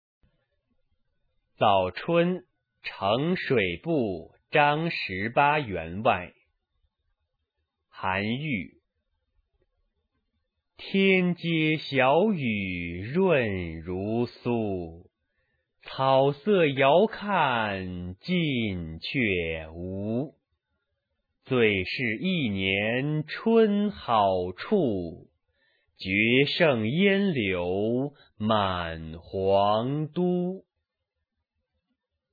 韩愈《早春呈水部张十八员外》原文和译文（含赏析、Mp3朗读）　/ 韩愈